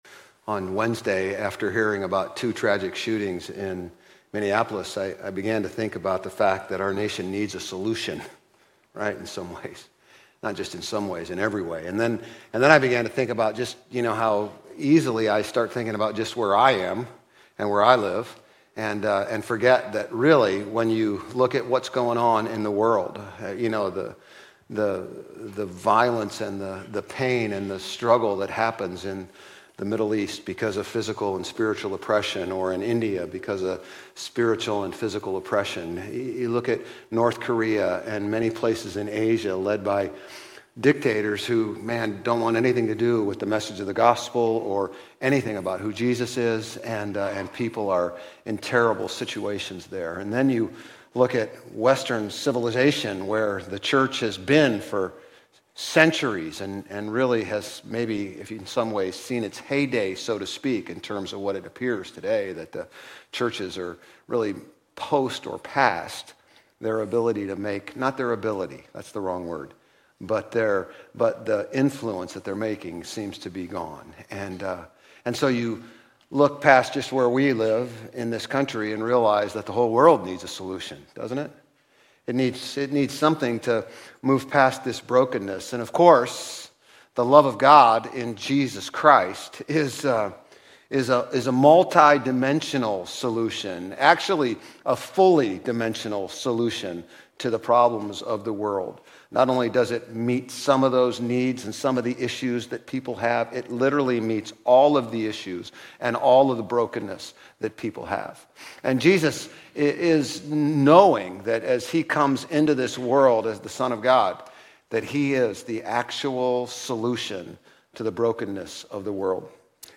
Grace Community Church Old Jacksonville Campus Sermons 8_31 Old Jacksonville Campus Aug 31 2025 | 00:31:11 1x 00:00 / 00:31:11 Subscribe Share RSS Feed Share Link Embed